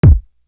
Impressions Kick.wav